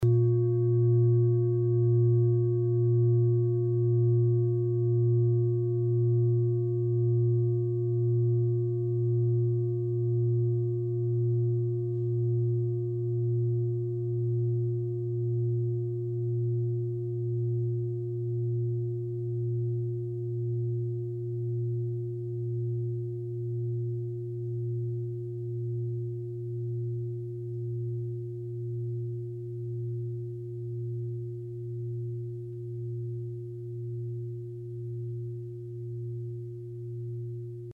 Klangschale TIBET Nr.21
Klangschale-Durchmesser: 28,9cm
Sie ist neu und ist gezielt nach altem 7-Metalle-Rezept in Handarbeit gezogen und gehämmert worden.
(Ermittelt mit dem Filzklöppel)
klangschale-tibet-21.mp3